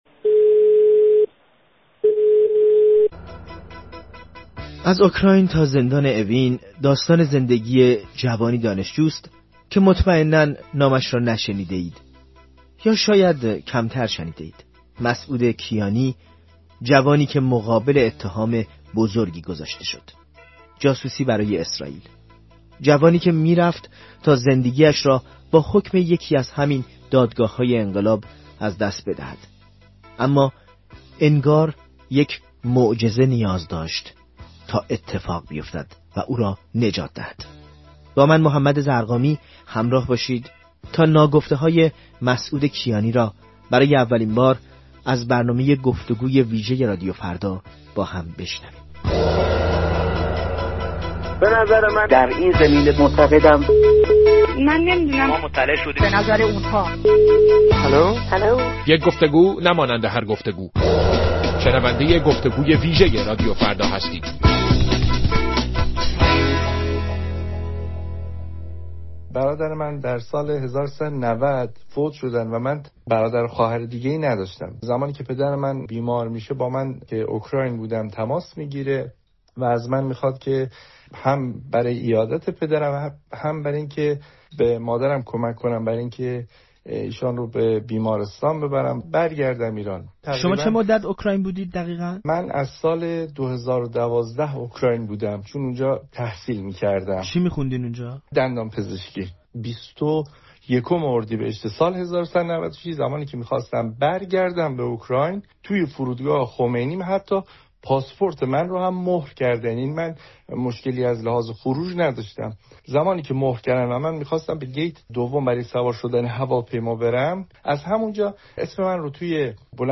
در گفت‌وگویی با رادیو فردا سکوت هشت ساله‌اش را شکست.